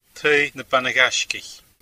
Pronunciation
Pronunciation: [ˈt̪ʰɤj ˈpanaɣaʃkʲɪç]